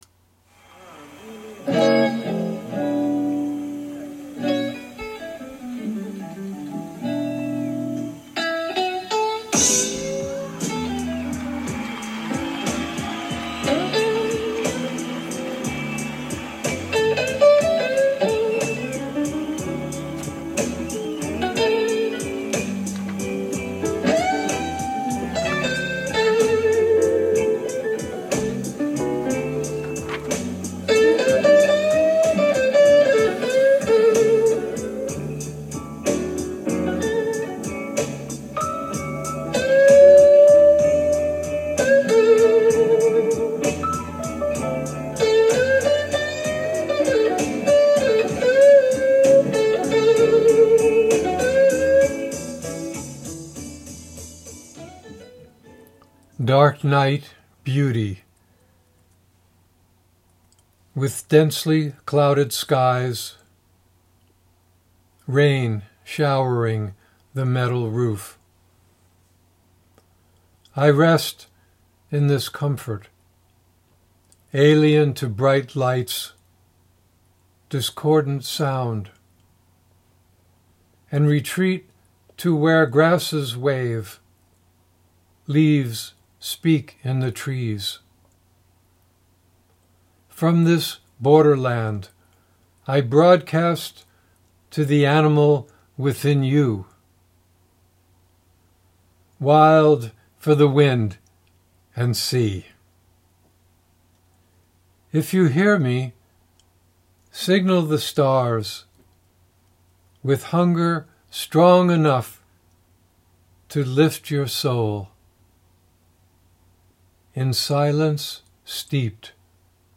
Reading of “Dark Night Beauty” with music by BB King